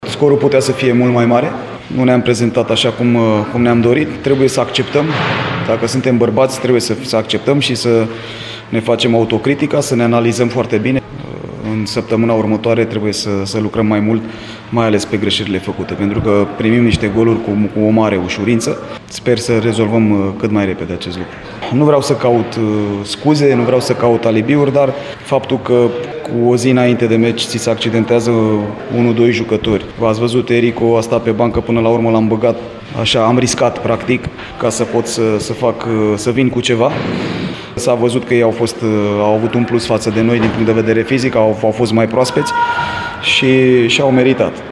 La sesiunea de declarați, de după meci, antrenorul învinșilor, Ilie Poenaru, a admis că prestația elevilor lui a fost slabă, iar rezultatul putea fi mai sever: